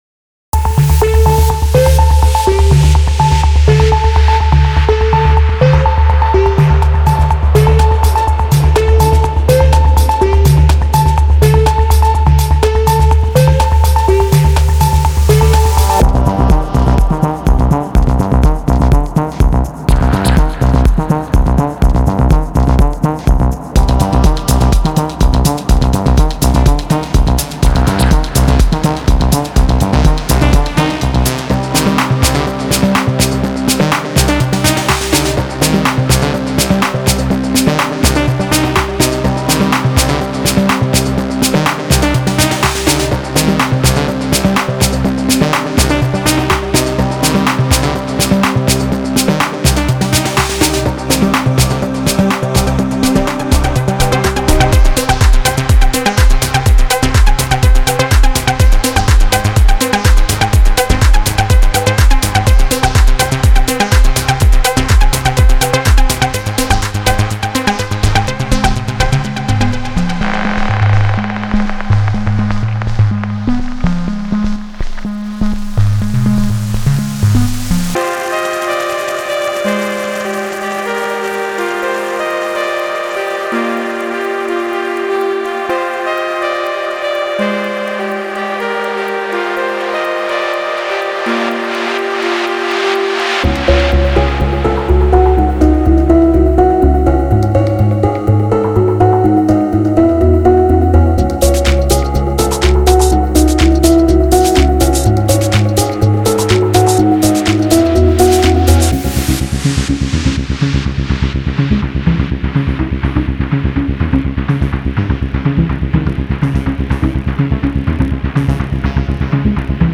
we deliver 100 hypnotic techno synth loops and melodies
– 100 analog synth loops (124 BPM)